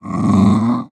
Minecraft Version Minecraft Version snapshot Latest Release | Latest Snapshot snapshot / assets / minecraft / sounds / mob / wolf / puglin / growl2.ogg Compare With Compare With Latest Release | Latest Snapshot
growl2.ogg